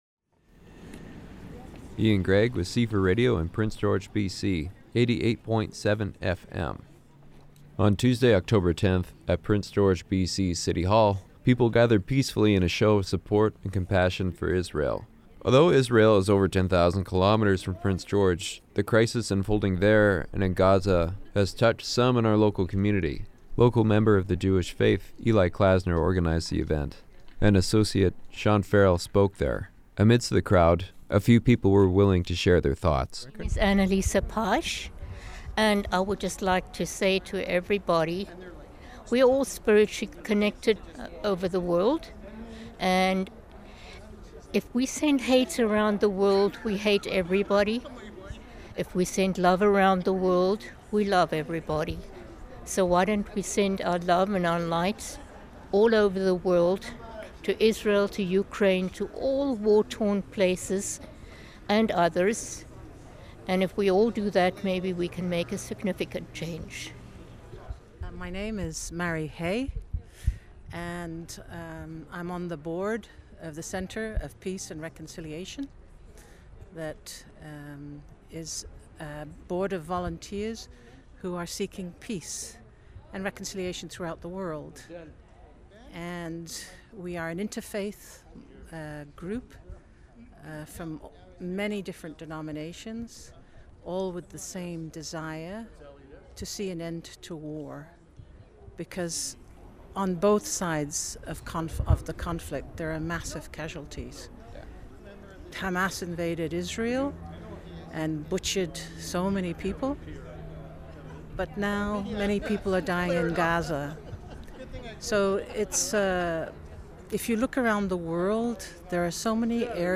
People gathered peacefully on Tuesday October 10 at Prince George City Hall in a show of support and compassion for Israel.